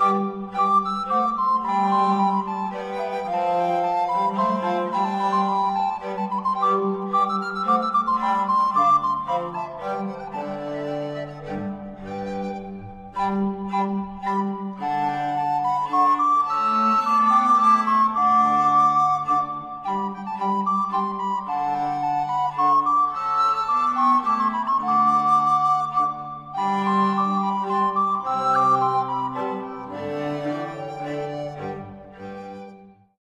fidel
lira korbowa, psałterium, bęben, viola da gamba
puzon